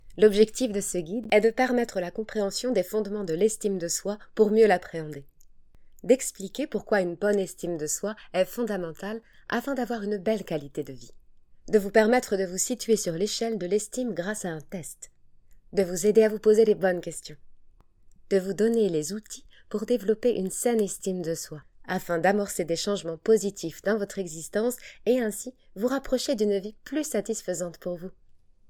voix off institutionnelle
- Mezzo-soprano